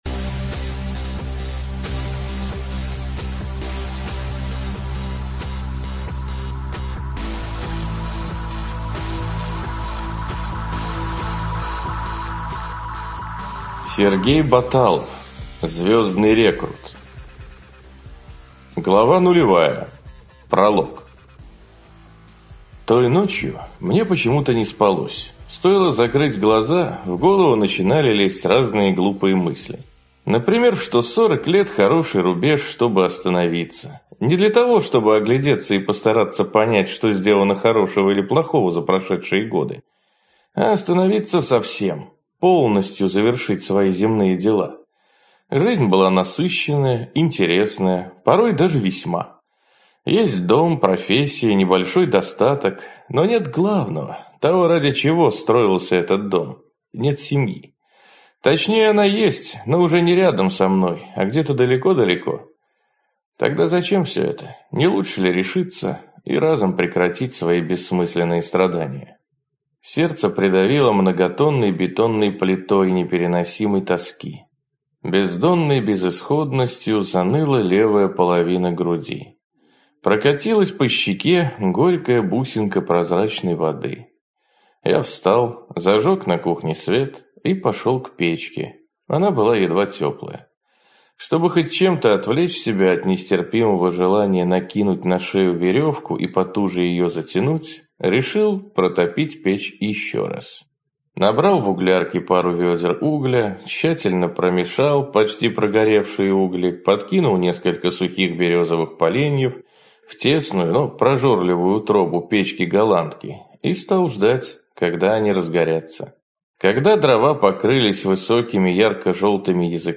Аудиокнига Звездный рекрут. Книга 1 | Библиотека аудиокниг